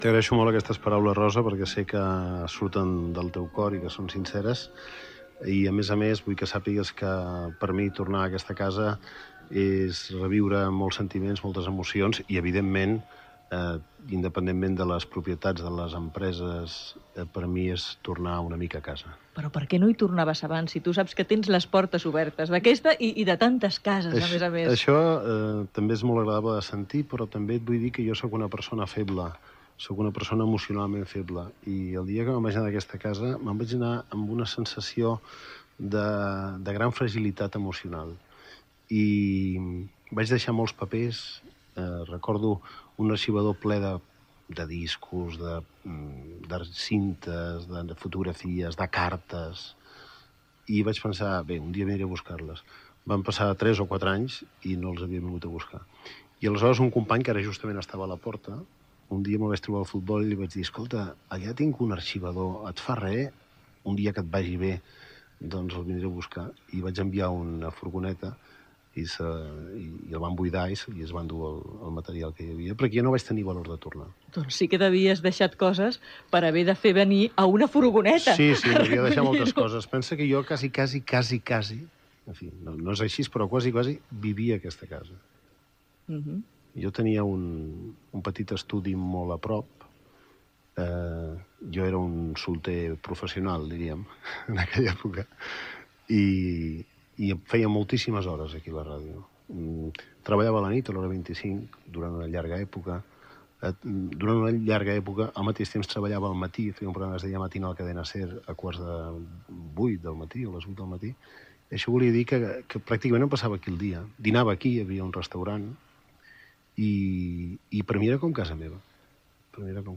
Entrevista al presentador Joaquim Maria Puyal. S'hi parla de quan van deixar Ràdio Barcelona i de la seva feina de jove a l'emissora.